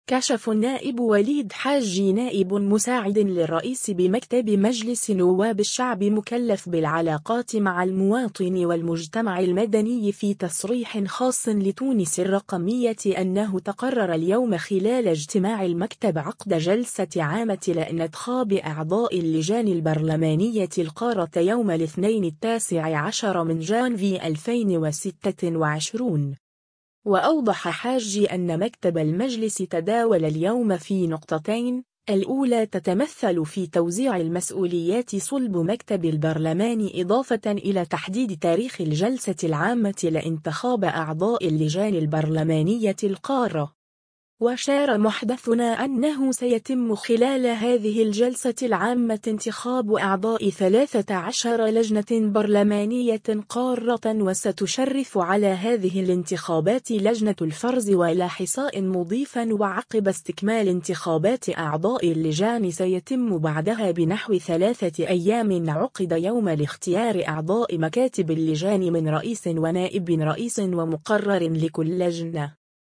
كشف النائب وليد حاجي نائب مساعد للرئيس بمكتب مجلس نواب الشعب مكلف بالعلاقات مع المواطن والمجتمع المدني في تصريح خاص لـ”تونس الرقمية” أنه تقرر اليوم خلال اجتماع المكتب عقد جلسة عامة لإنتخاب أعضاء اللجان البرلمانية القارة يوم الاثنين 19 جانفي 2026.